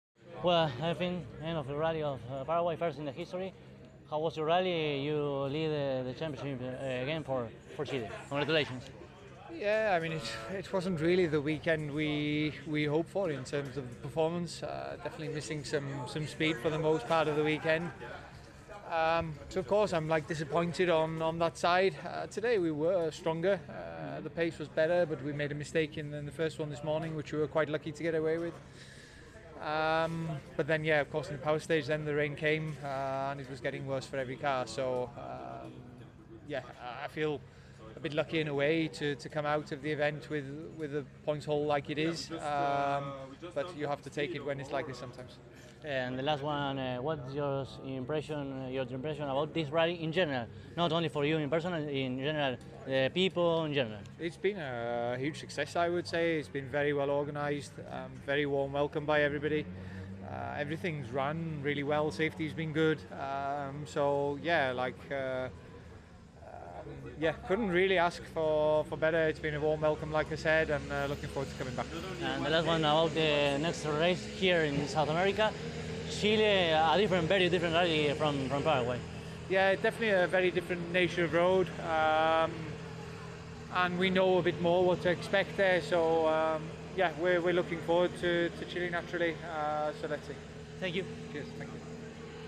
El domingo, luego de la carrera, dialogamos con los tres primeros del clasificador general, que los podrás escuchar en esta nota.
Así entonces, a continuación, podrás escuchar las entrevistas que realizamos (en inglés) con cada uno de ellos: